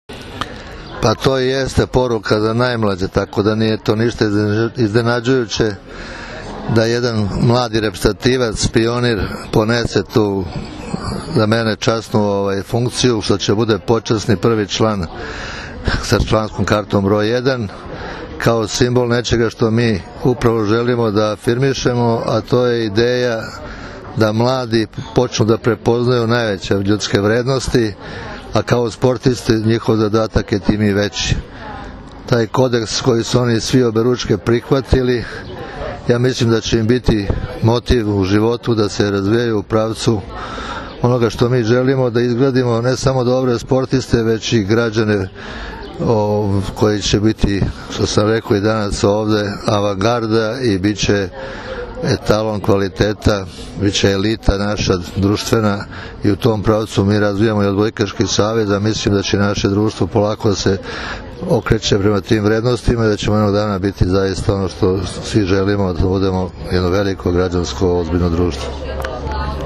PIONIRI – PRVENSTVO SRBIJE 2016. – FINALNI TURNIR
IZJAVA